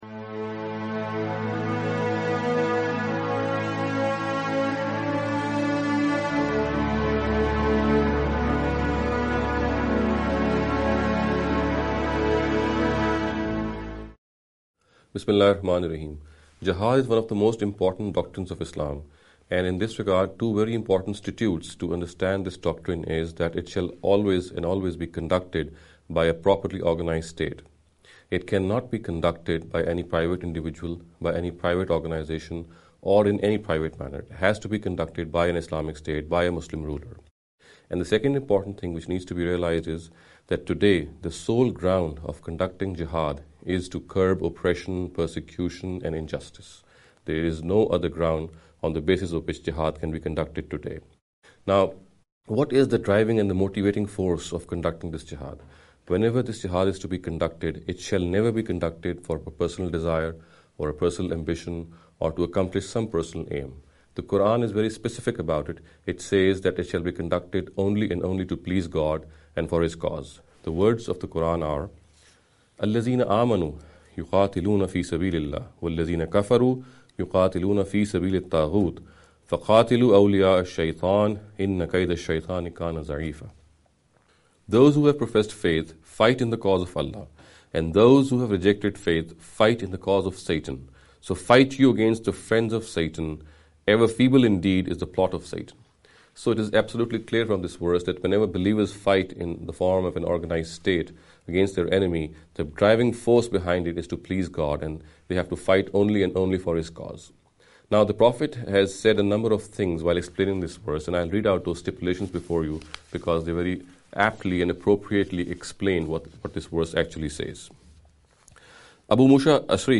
This lecture series will deal with some misconception regarding the Islam and Jihad.